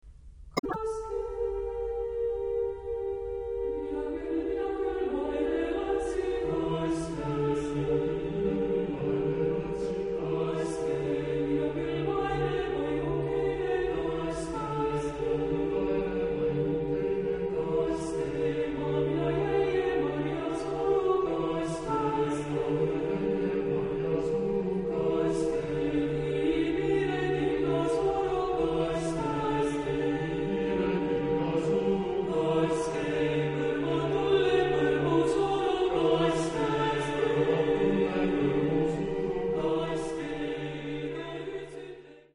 Genre-Style-Form: Partsong ; Folk music ; Secular
Type of Choir: SSAB  (4 mixed voices )
Tonality: dorian